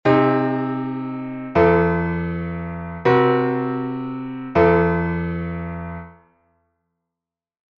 8edo V - i like progression, audio